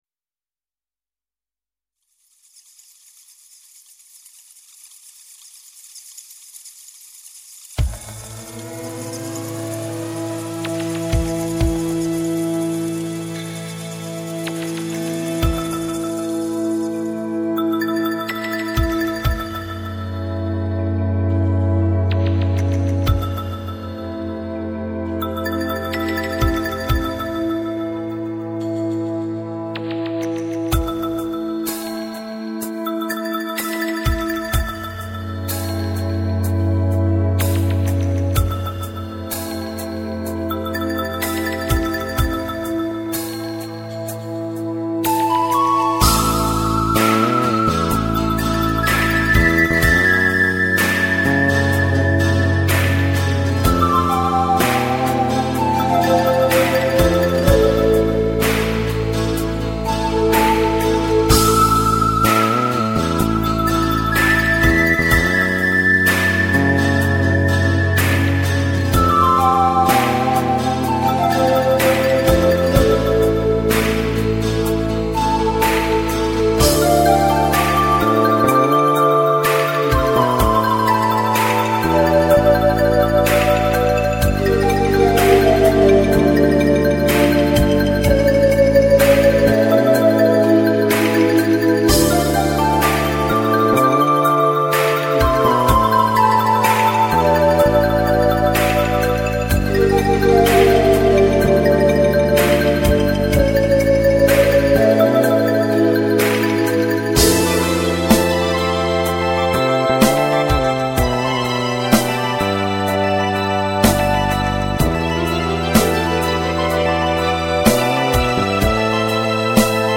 Инструментальная композиция сделана в 1999 году